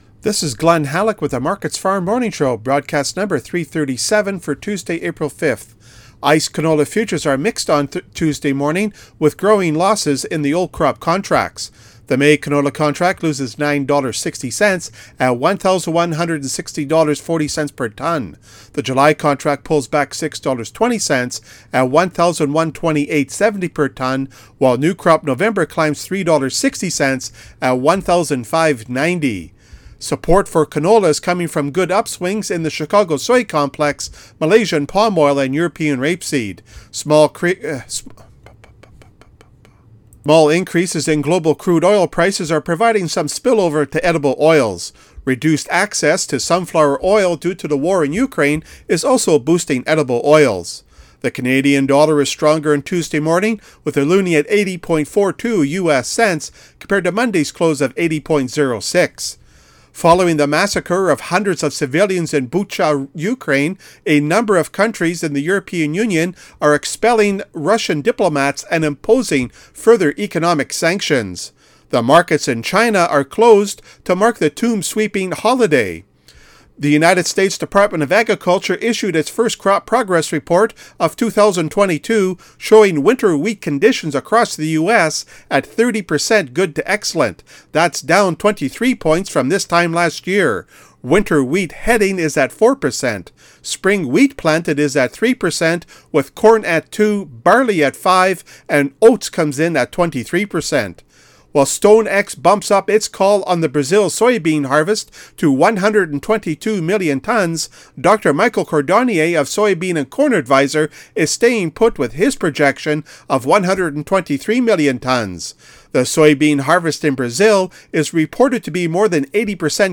MarketsFarm Morning Radio Show April 5 - AgCanada